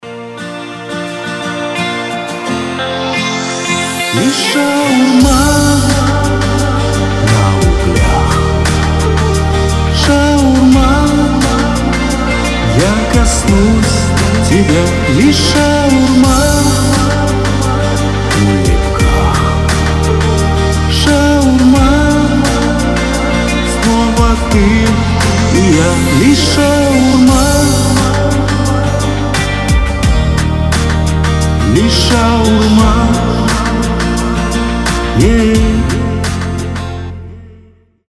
• Качество: 320, Stereo
гитара
душевные
веселые